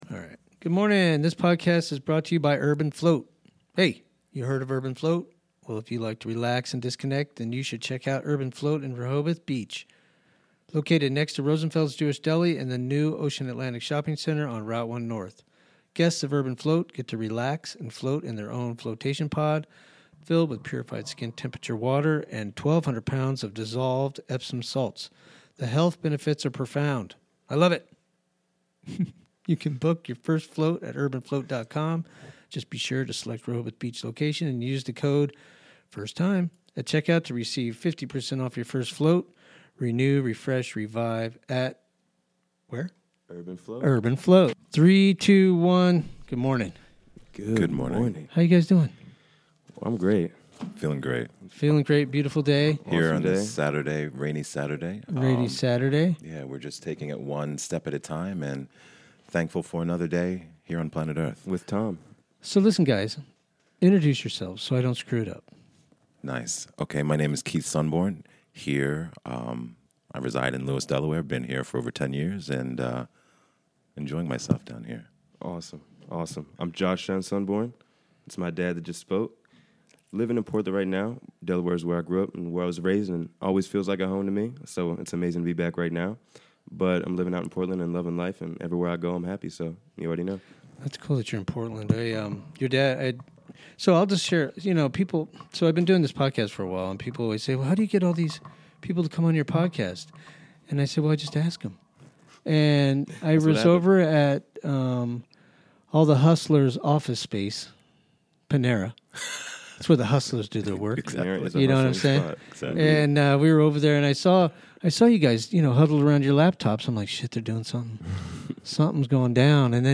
Sitting down with local folks.